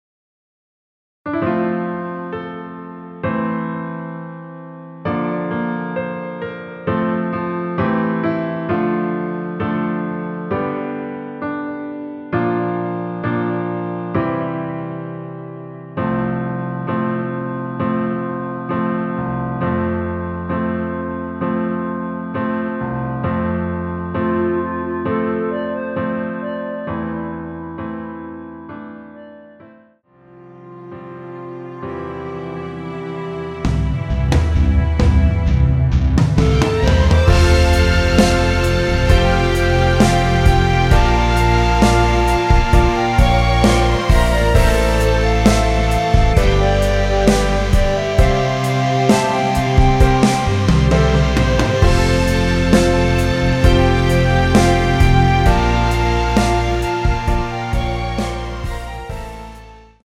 멜로디 MR입니다.
음정과 박자 맞추기가 쉬워서 노래방 처럼 노래 부분에 가이드 멜로디가 포함된걸
앞부분30초, 뒷부분30초씩 편집해서 올려 드리고 있습니다.
중간에 음이 끈어지고 다시 나오는 이유는